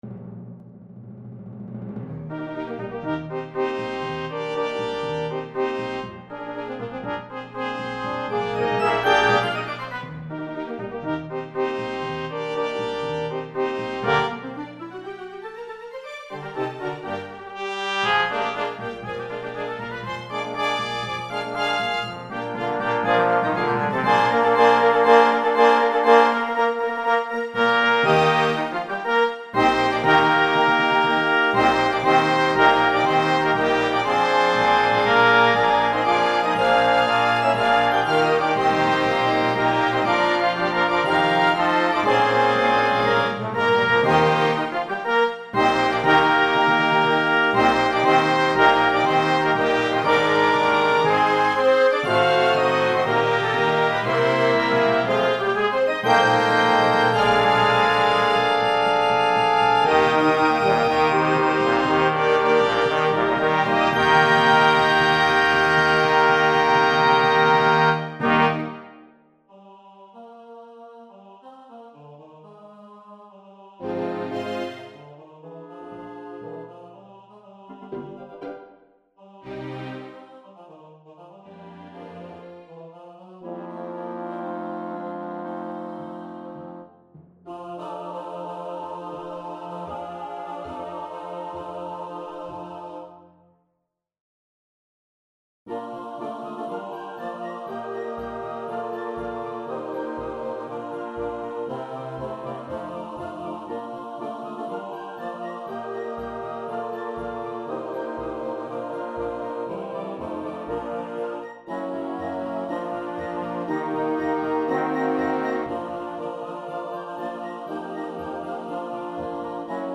Company, Governor